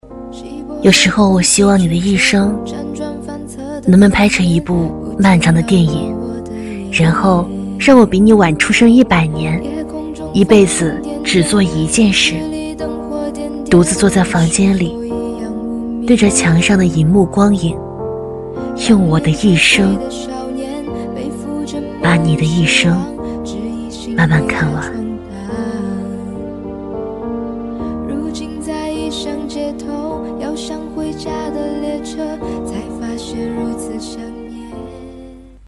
v155-温柔女声走心独白音频
v155-温柔女声走心独白音频.mp3